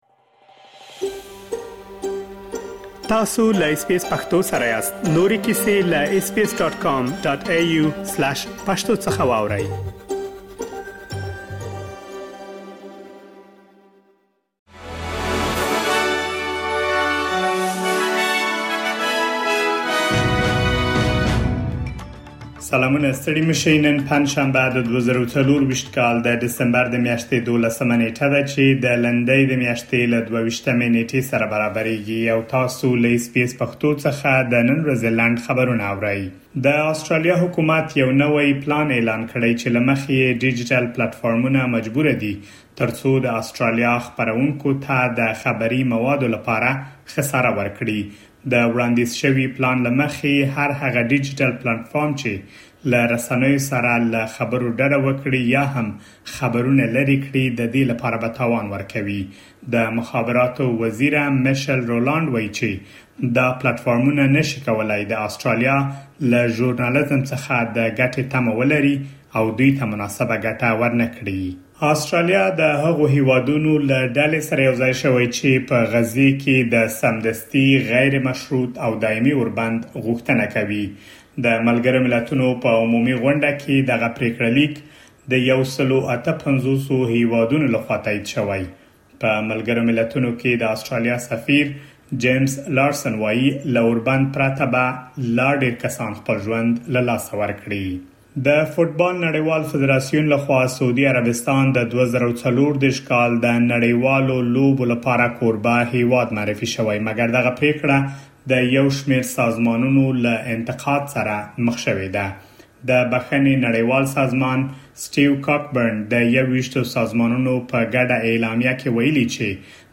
د اس بي اس پښتو د نن ورځې لنډ خبرونه |۱۲ ډسمبر ۲۰۲۴
د اس بي اس پښتو د نن ورځې لنډ خبرونه دلته واورئ.